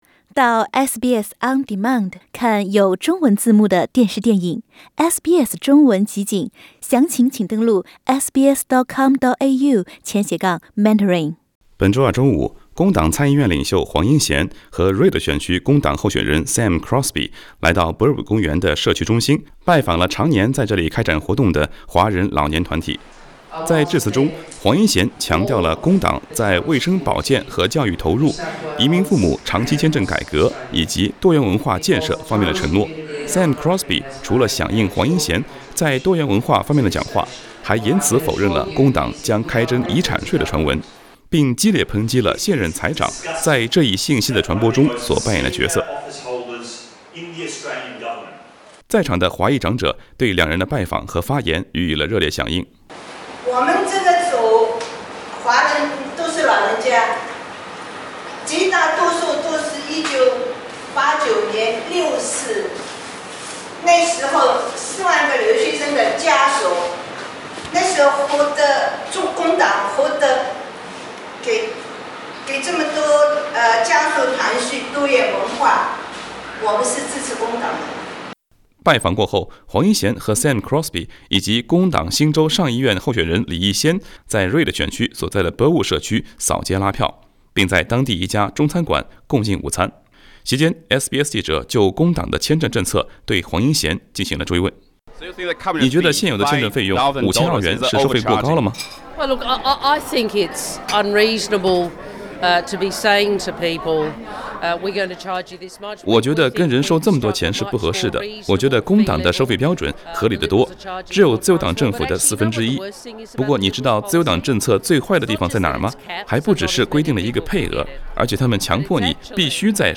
Penny Wong in Burwood, western Sydney, ahead of federal election Source: SBS Mandarin SBS普通话节目记者在现场，请他们对移民部长大卫-高民对工党移民父母长期签证政策的质疑做出回应。
SBS普通话节目记者就工党的签证政策，对黄英贤进行了追问。